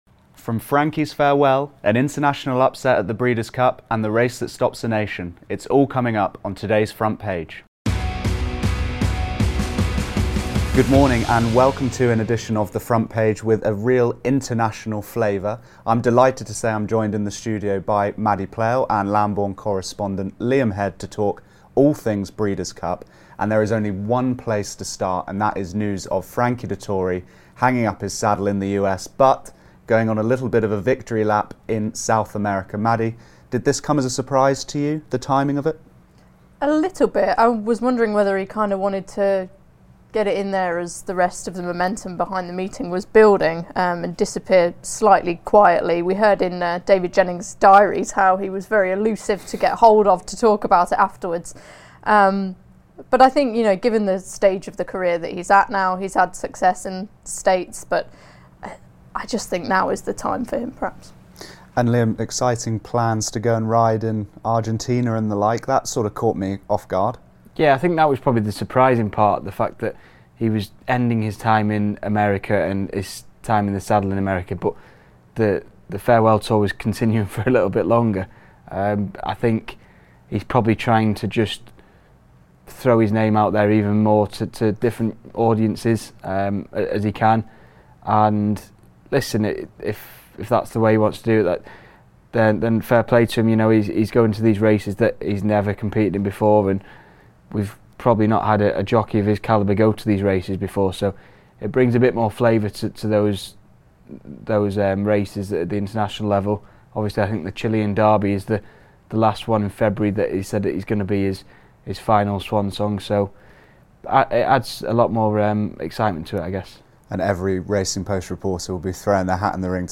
The panel also reflects on a remarkable weekend for European trainers at the Breeders' Cup, with Willie Mullins, Aidan O'Brien, Francis Graffard and Charlie Appleby among those on the scoresheet, and looks ahead to Tuesday's Melbourne Cup.